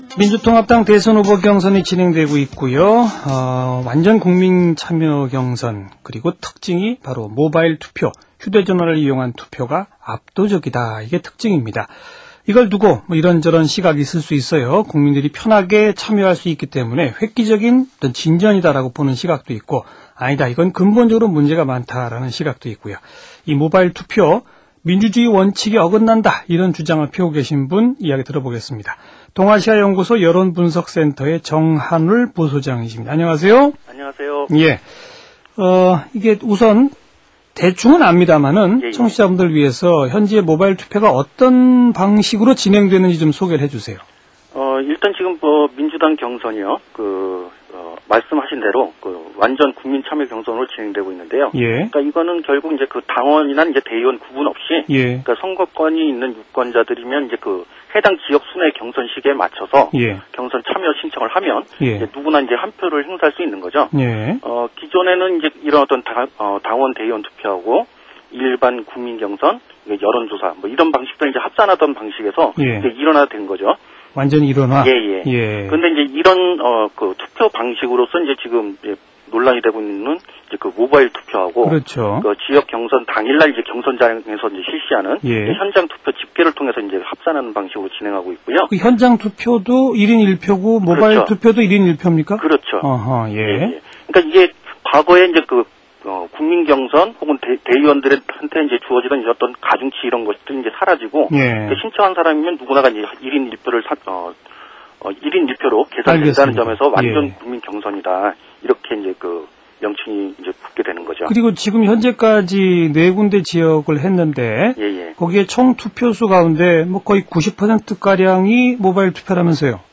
◎ [인터뷰 2] "모바일투표